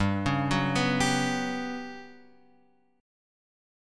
G Major 7th Flat 5th